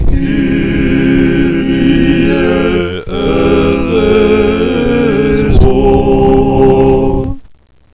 Wave files are sounds that you record with a microphone. The clicking noises in the background of some songs is my metronome.